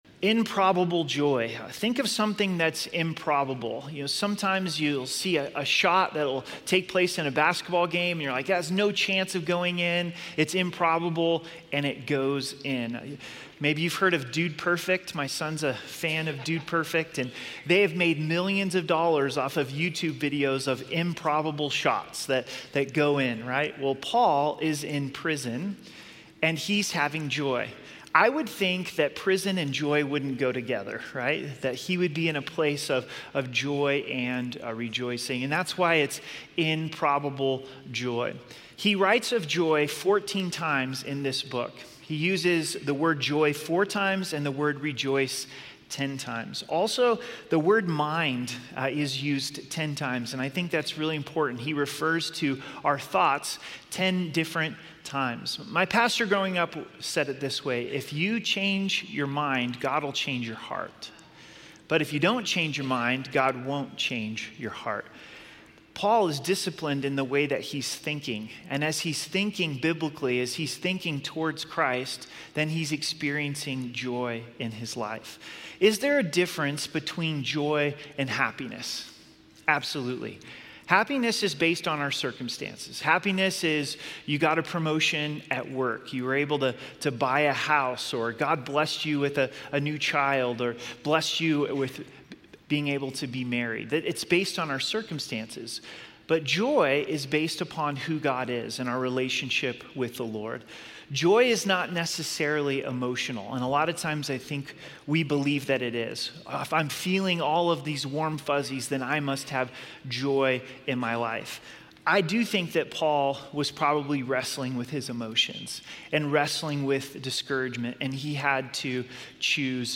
A message from the series "Philippians."